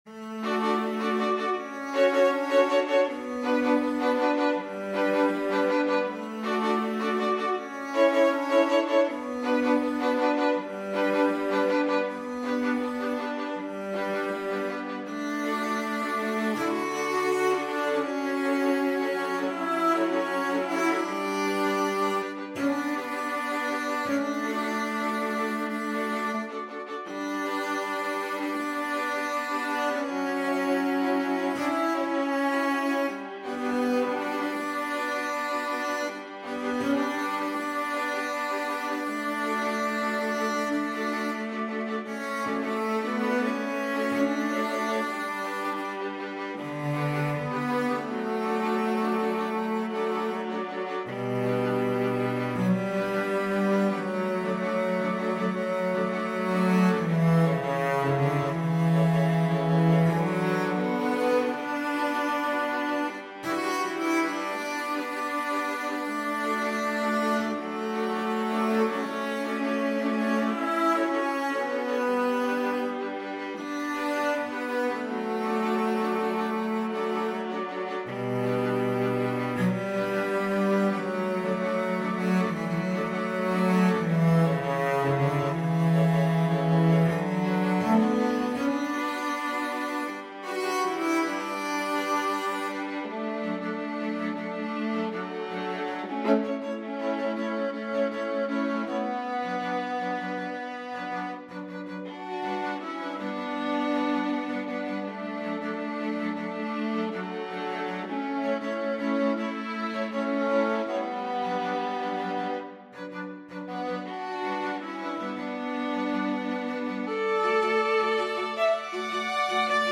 String Trio Buy now!